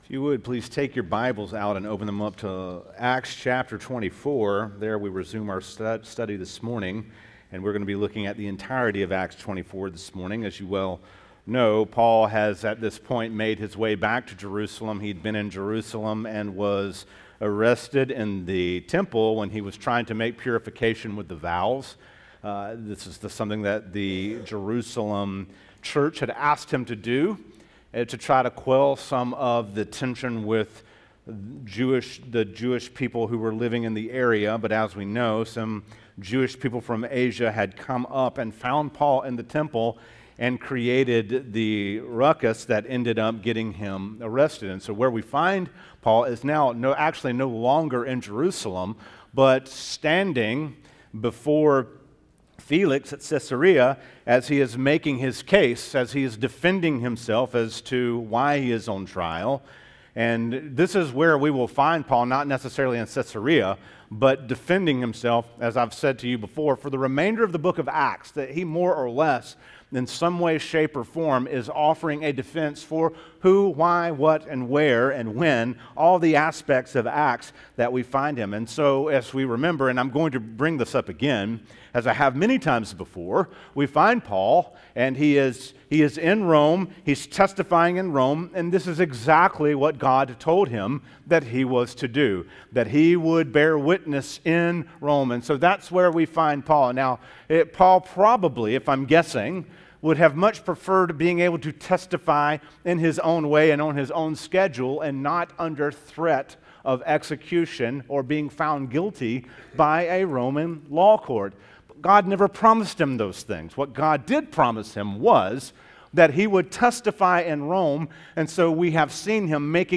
teaches from the series: Acts, in the book of Acts, verse 24